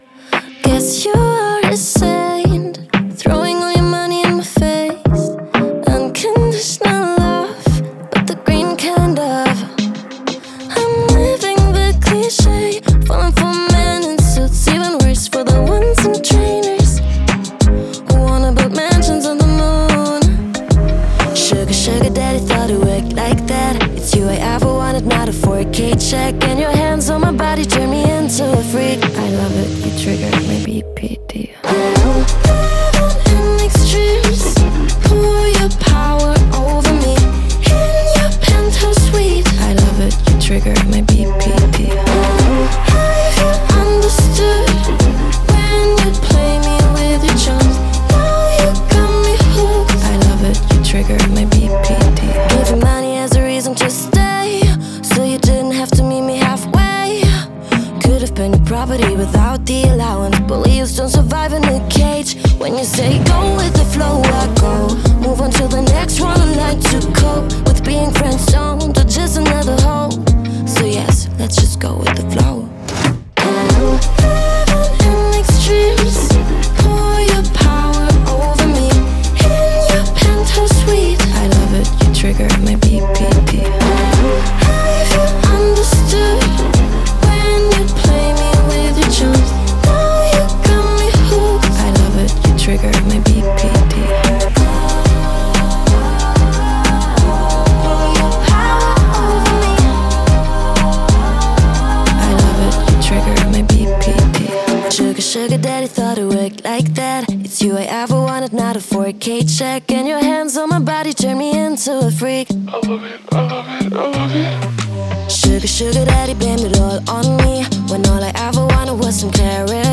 Sprecherin, Synchronsprecherin, Sängerin